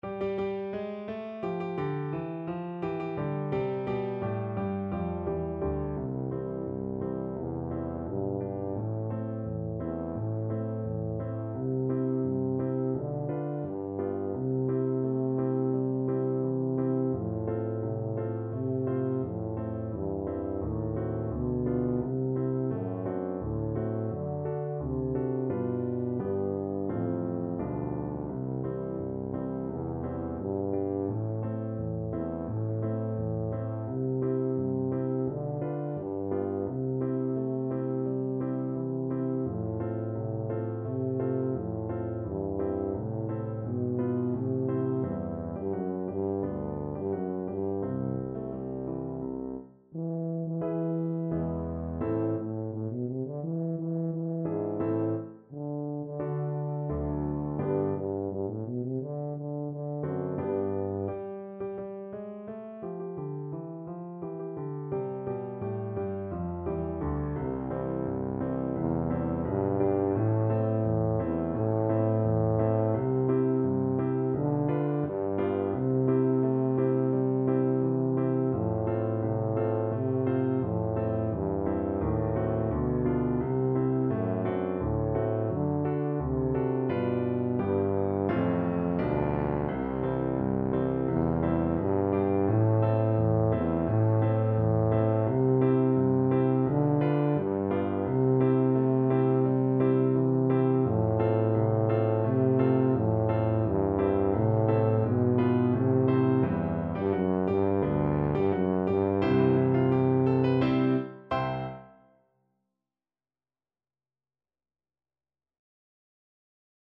Pop Chas A Zimmermann Anchors Aweigh Tuba version
2/2 (View more 2/2 Music)
C major (Sounding Pitch) (View more C major Music for Tuba )
March Tempo - Moderato = c.86